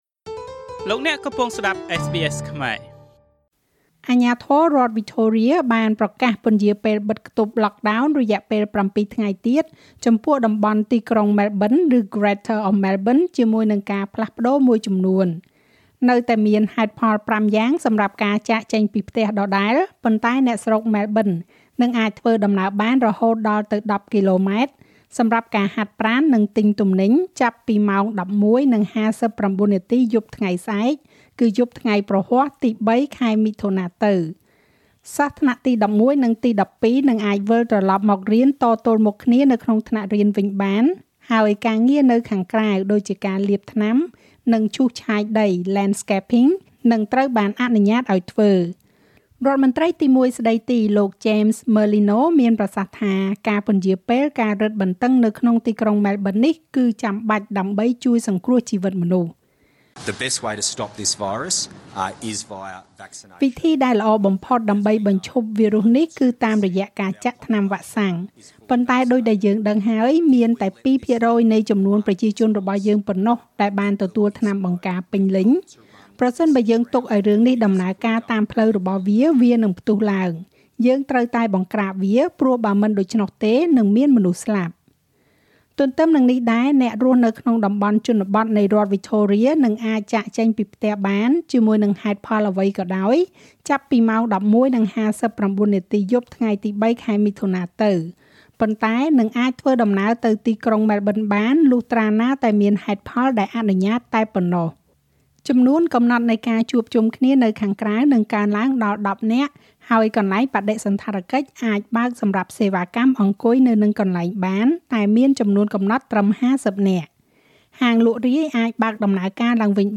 ស្តាប់មាតិកាព័ត៌មានចុងក្រោយបង្អស់ក្នុងប្រទេសអូស្រ្តាលីពីវិទ្យុSBSខ្មែរ។